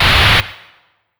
Scrape.wav